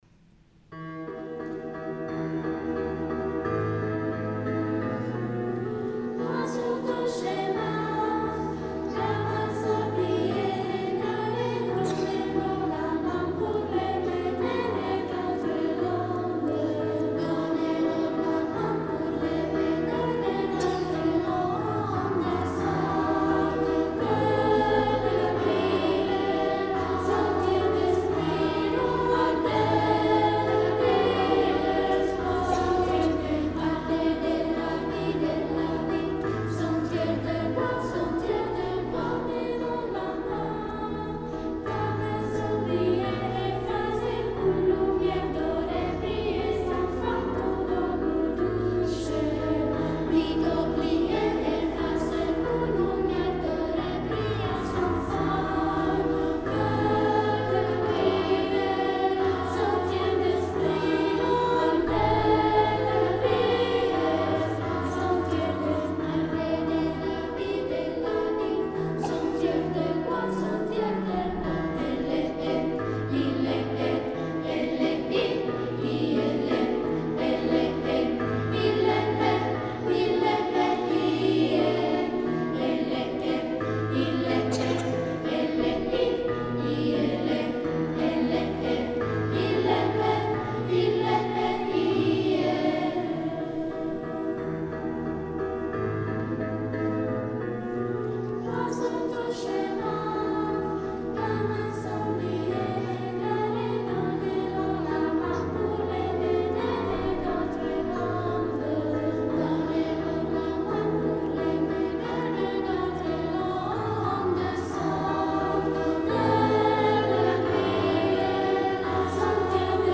6° rassegna corale